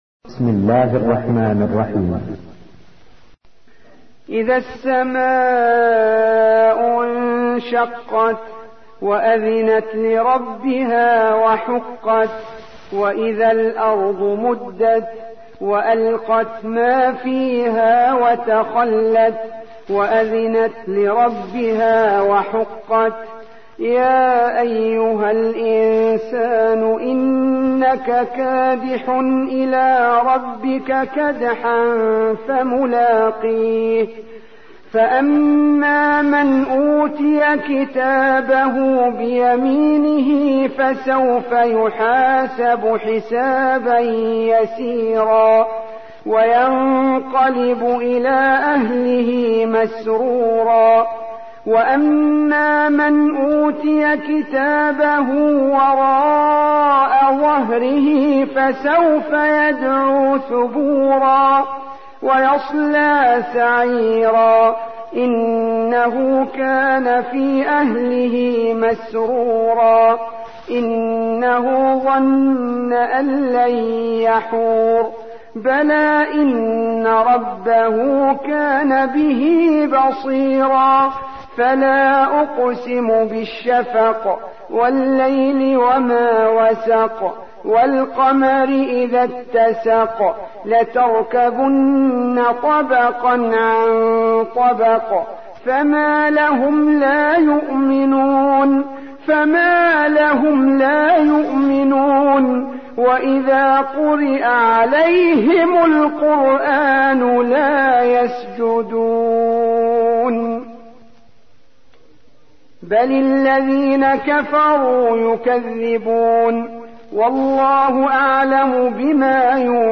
84. سورة الانشقاق / القارئ